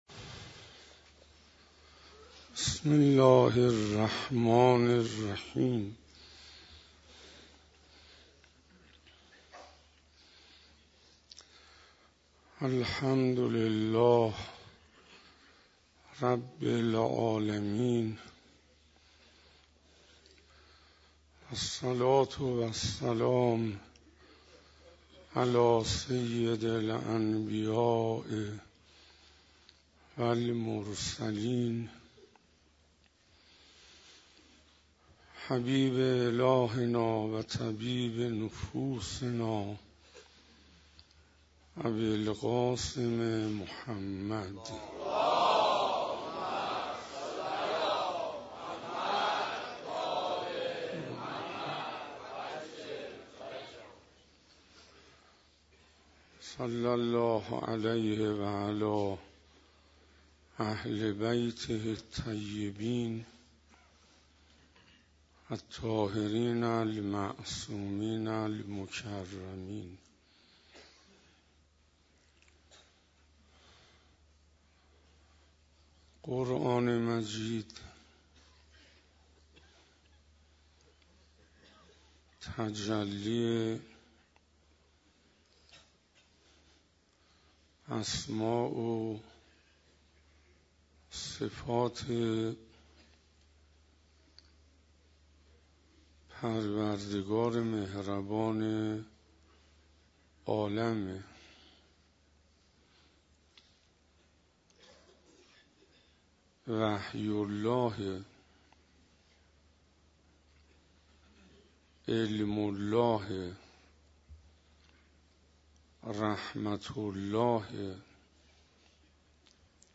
ویژه برنامه شهادت حضرت زهرا (س) - شنبه 20 بهمن - مسجد هدایت